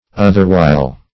Search Result for " otherwhile" : The Collaborative International Dictionary of English v.0.48: Otherwhile \Oth"er*while`\, Otherwhiles \Oth"er*whiles`\, adv. At another time, or other times; sometimes; occasionally.